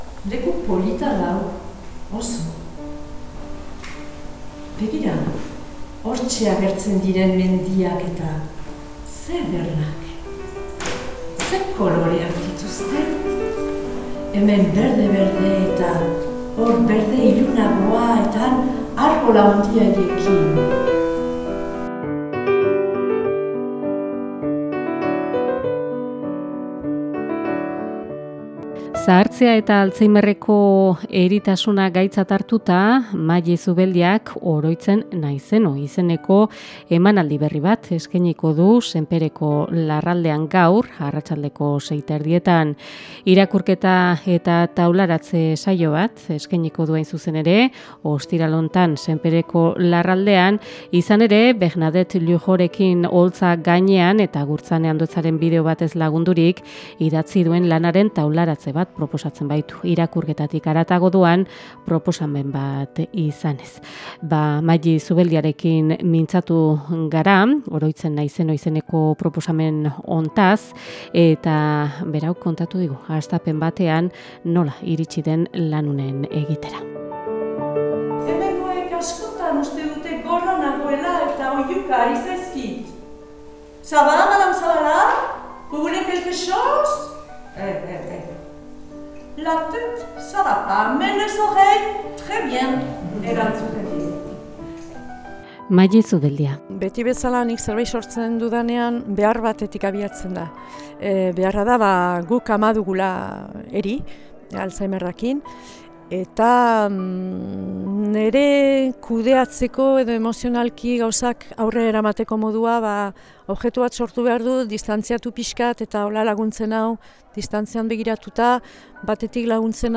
IRRATIEN TARTEA | “Oroitzen naizeno” izeneko ikusgarriaz egindako erreportaia ekarri digu Antxeta Irratiak